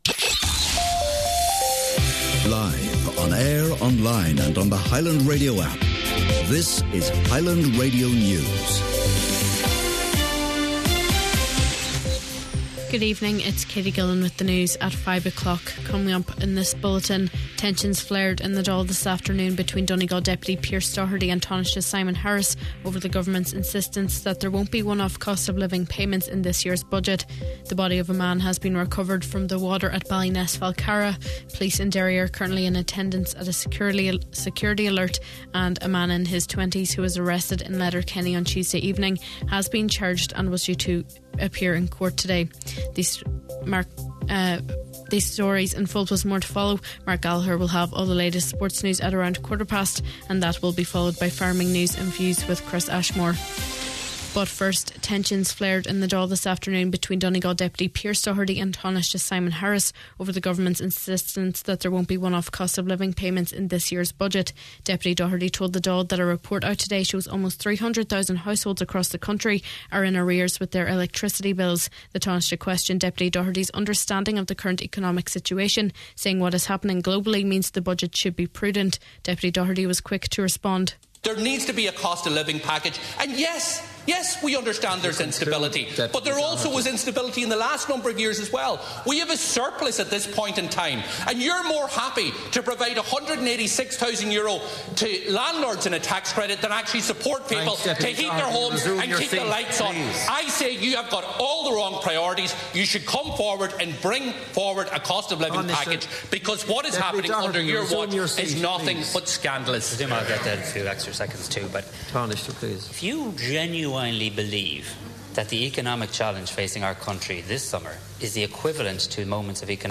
Main Evening News, Sport, Farming News and Obituaries – Thursday July 17th